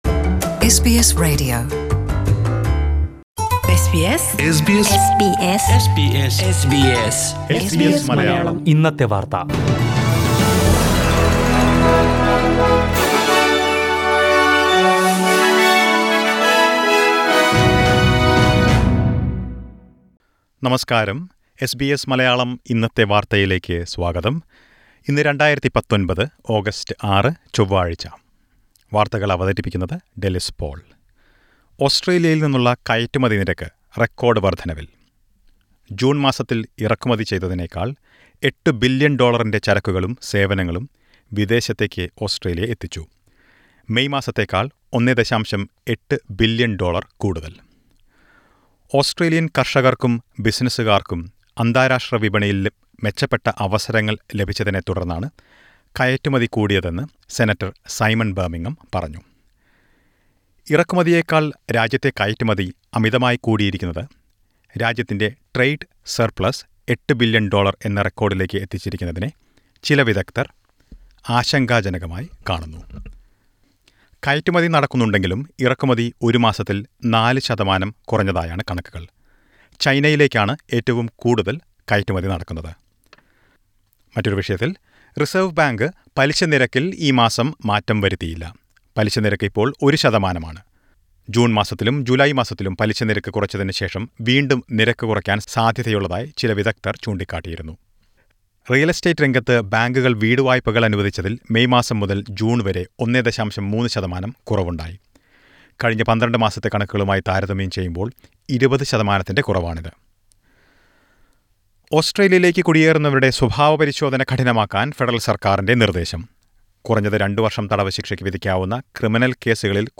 SBS Malayalam Today's News: August 6 , 2019